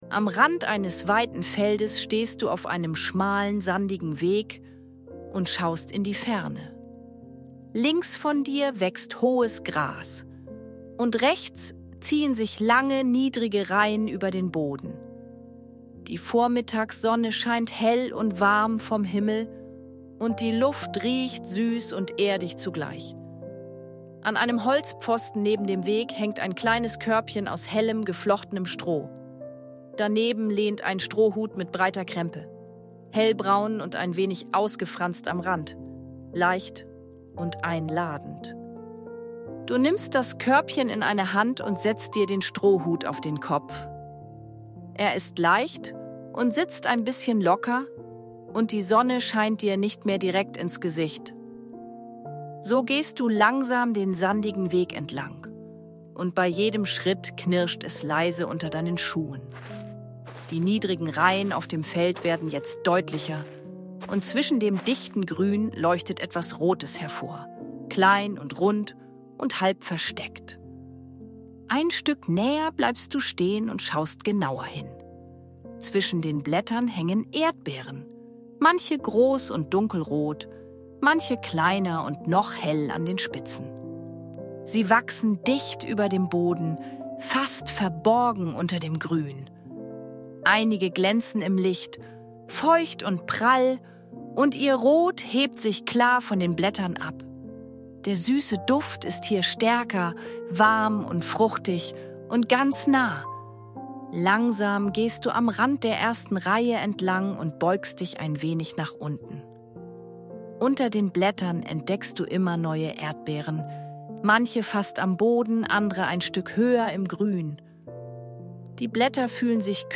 Sanfte Fantasiereisen mit leiser Hintergrundmusik – zum Malen und kreativen Entspannen